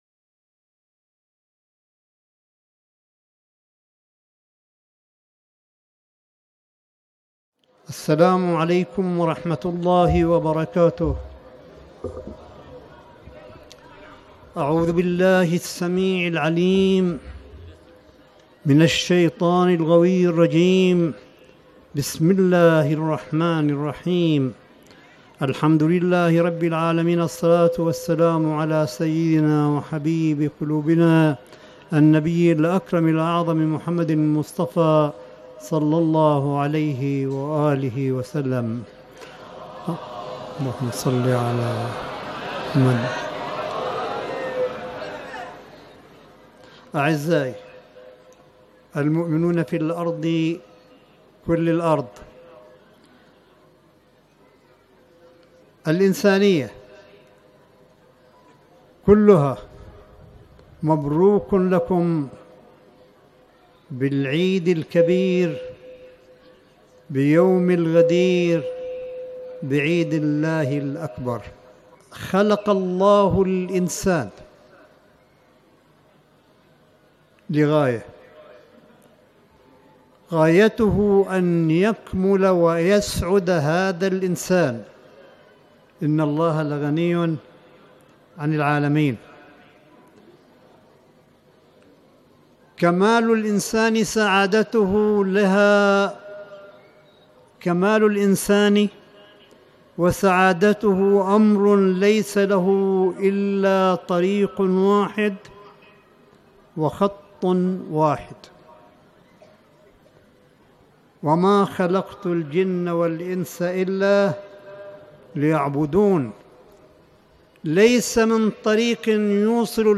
ملف صوتي لكلمة آية الله الشيخ عيسى قاسم في عيد الغدير في الحرم الرضوي بمشهد المقدسة – ليلة عيد الغدير ١٤٤٠هـ / ١٩ اغسطس ٢٠١٩م